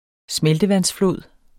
Udtale [ ˈsmεldəˌvans- ]